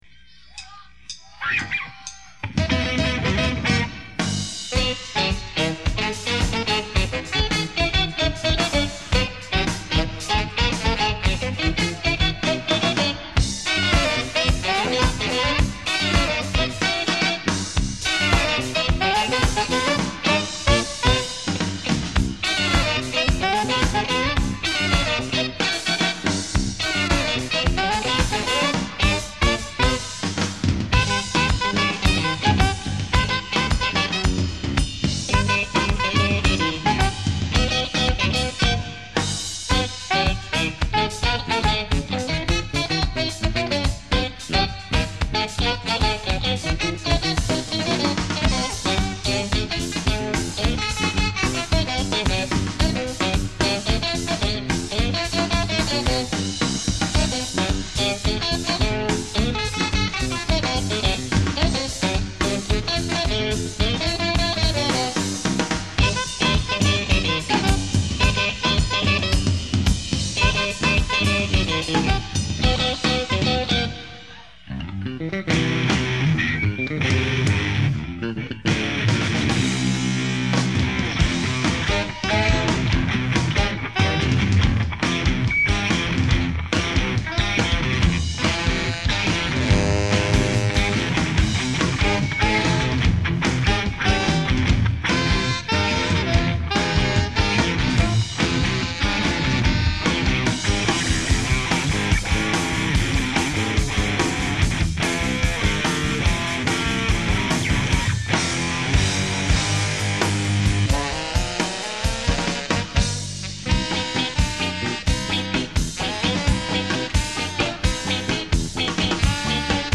écoutez bien la basse!!!!